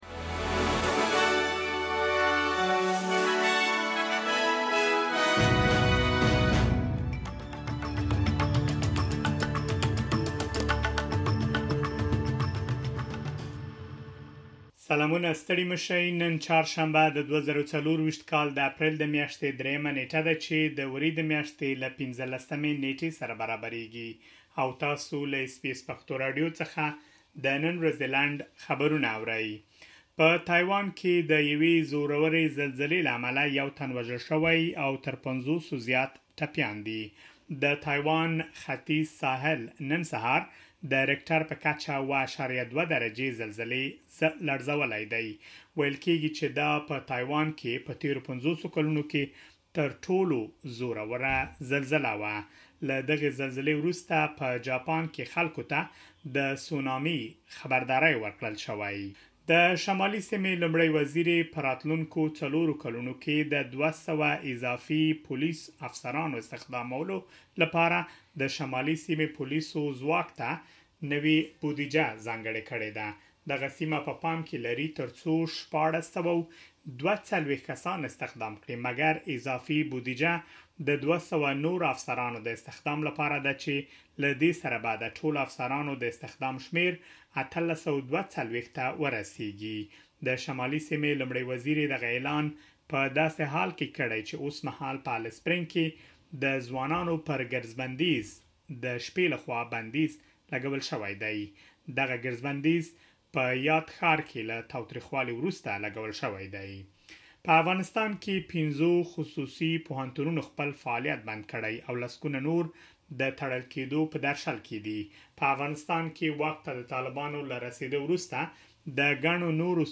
د اس بي اس پښتو راډیو د نن ورځې لنډ خبرونه|۳ اپریل ۲۰۲۴